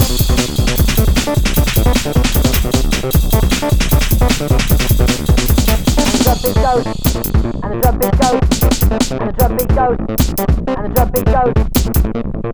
33 Drumbeat Goes-d.wav